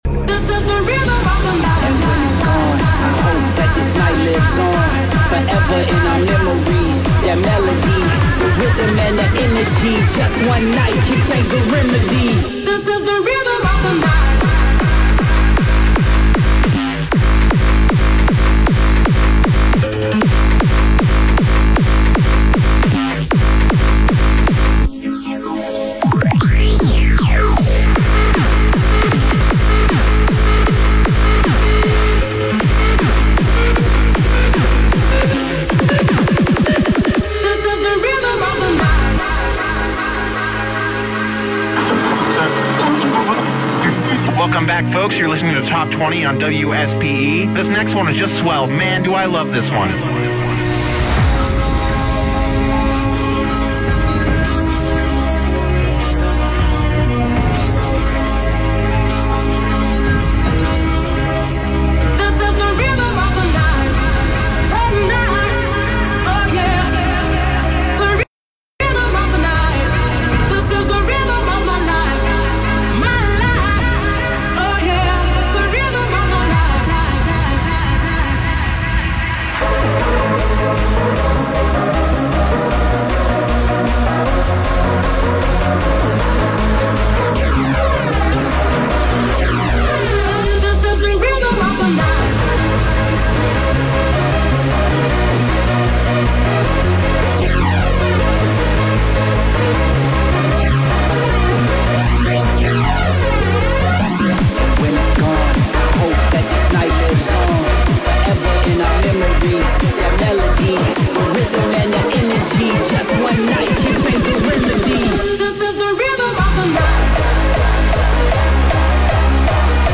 Live Set/DJ mix